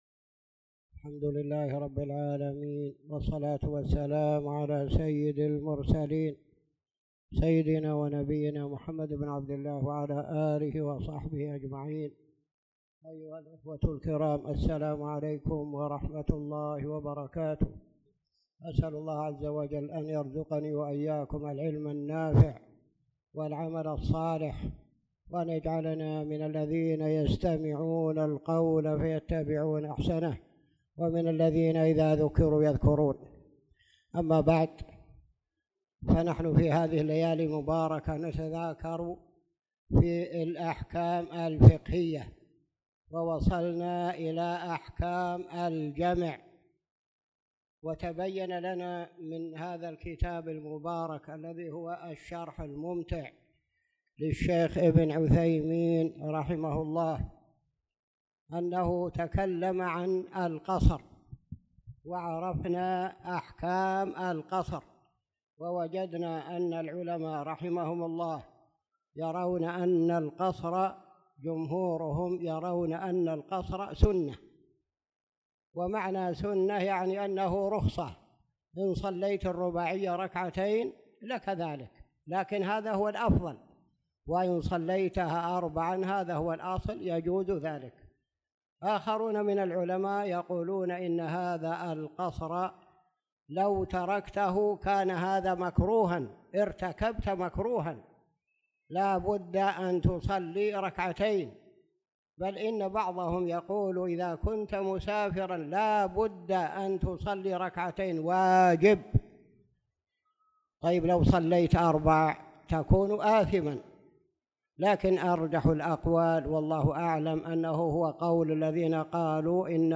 تاريخ النشر ١ شعبان ١٤٣٧ هـ المكان: المسجد الحرام الشيخ